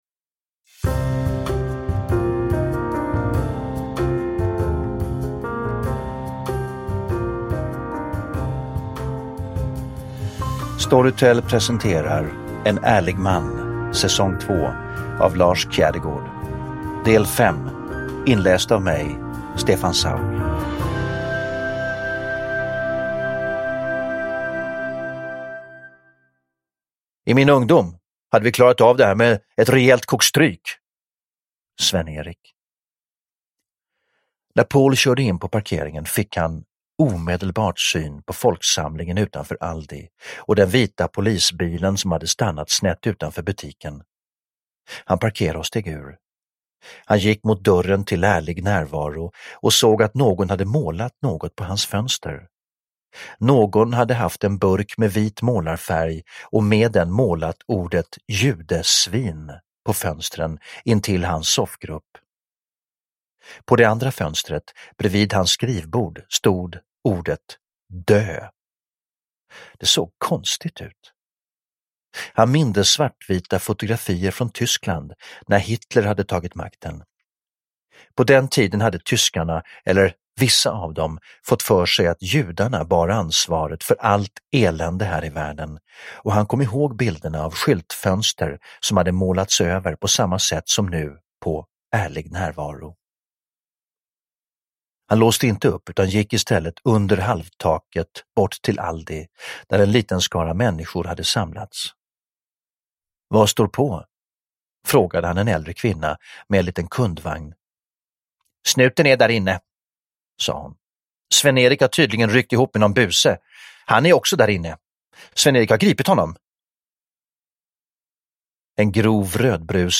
Uppläsare: Stefan Sauk